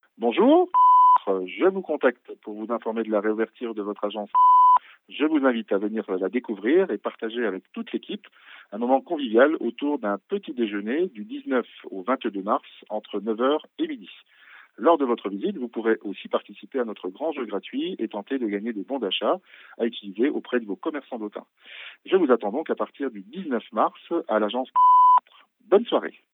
Un mailing vocal, aussi appelé SMS vocal, VMS ou encore Message répondeur, consiste à déposer en masse un message vocal directement sur le répondeur mobile de vos contacts.